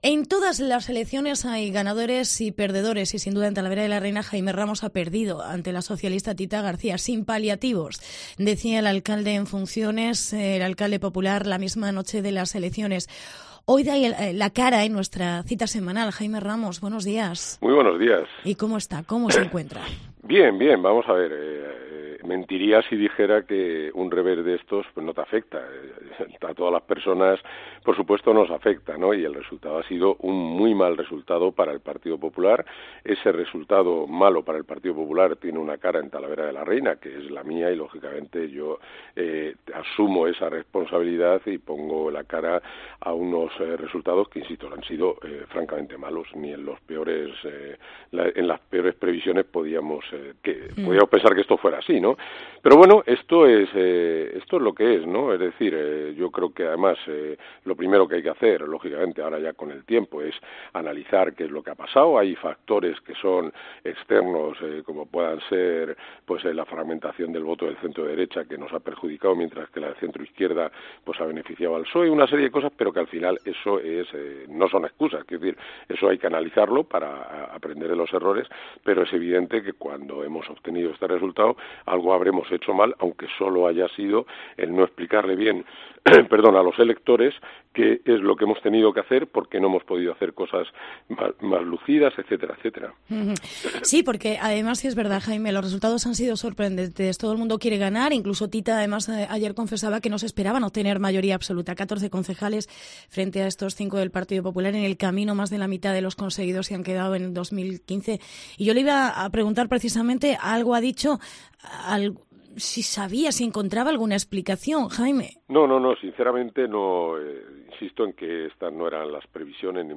Jaime Ramos da la cara y asume la responsabilidad del batacazo electoral en Talavera. Entrevista